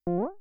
phaseJump1.ogg